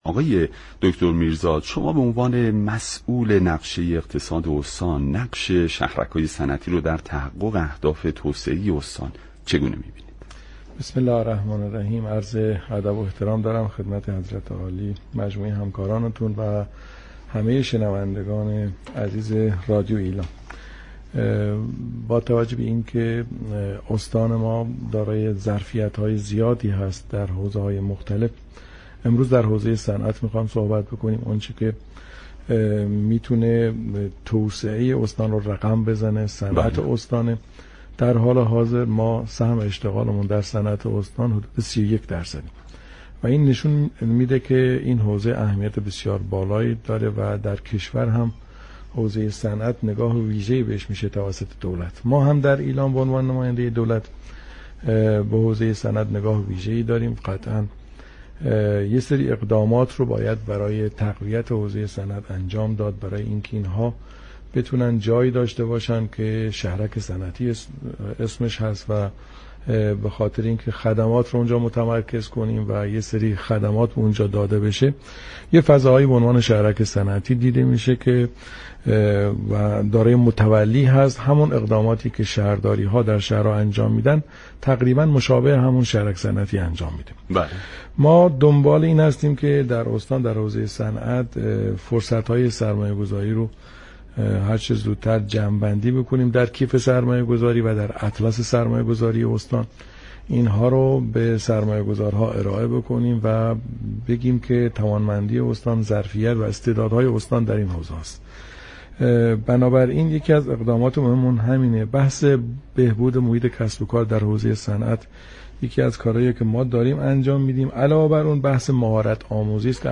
معاون اقتصادی استانداری ایلام در برنامه ایران امروز گفت: استانداری برای تقویت حوزه صنعت شهرك‌های صنعتی را ایجاد كرده است.